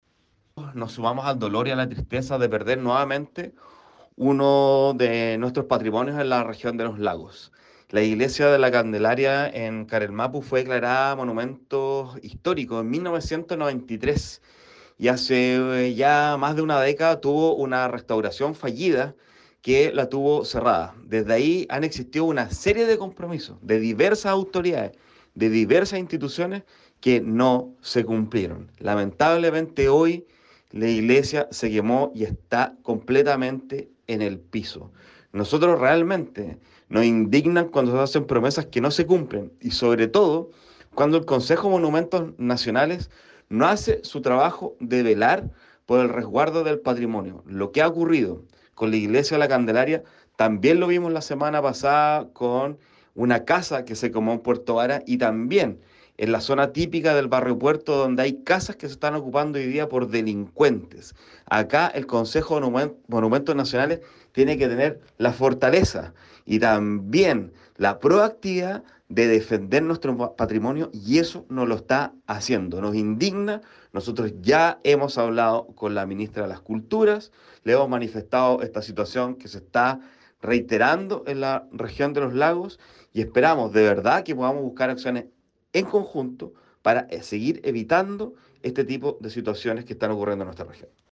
Desde la vereda política, el diputado Alejandro Bernales cuestionó la poca eficacia en las acciones llevadas a cabo por el Consejo de Monumentos Nacionales en torno a este templo histórico que ahora se perdió por este incendio.